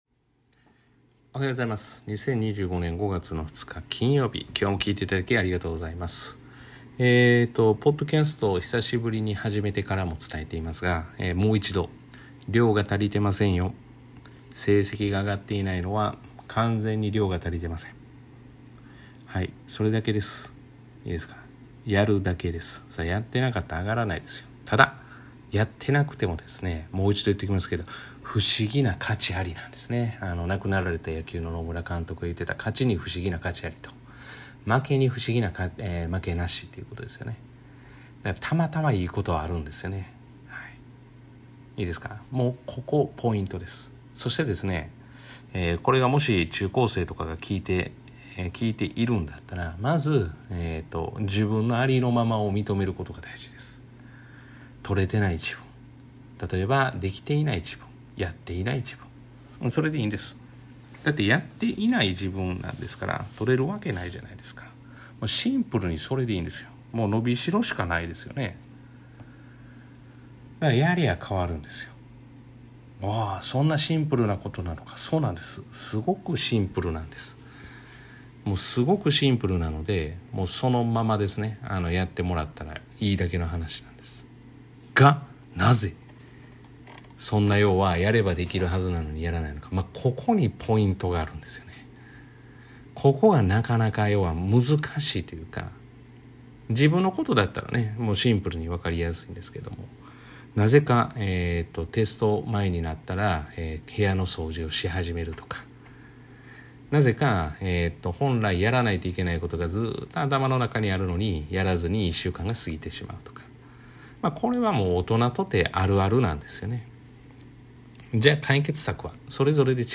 「塾に頼らず勉強できるようにする！」を本気で実践している塾講師が日常で起きる出来事を「ゆるーく」話します。